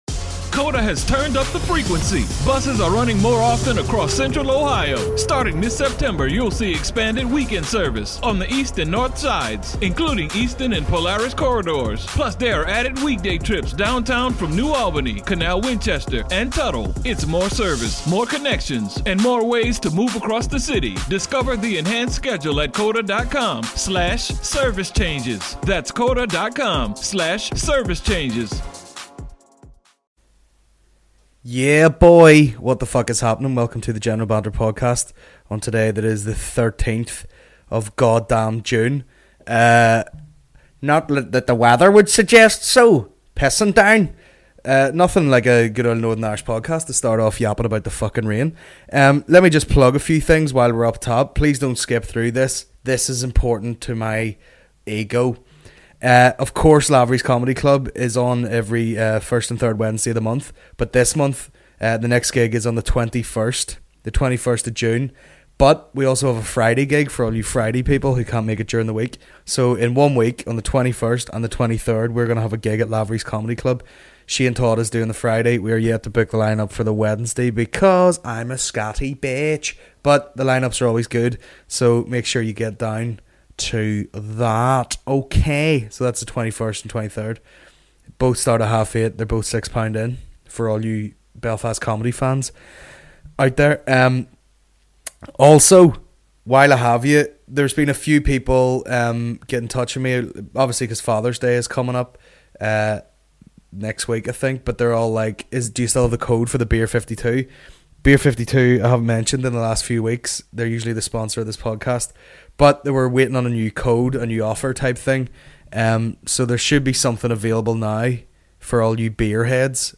Tueday 13th June 2017 General Banter Podcast General Banter Podcast Comedy 4.8 • 1.1K Ratings 🗓 13 June 2017 ⏱ 88 minutes 🔗 Recording | iTunes | RSS 🧾 Download transcript Summary This week - Injured backs and racial attacks.